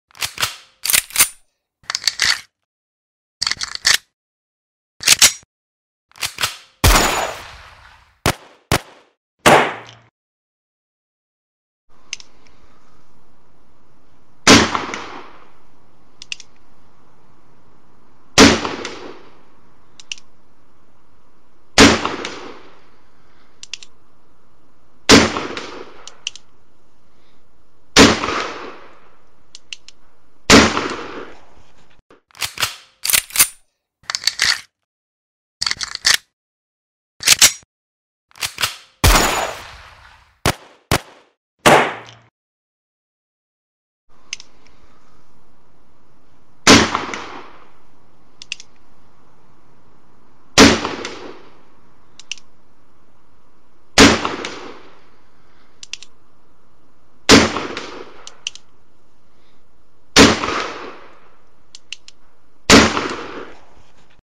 Som de Tiro de Pistola Ponto 40
Categoria: Sons de armas de combate
Este áudio captura com perfeição o impacto de um disparo poderoso, como o de uma Desert Eagle.
som-de-tiro-de-pistola-ponto-40-pt-www_tiengdong_com.mp3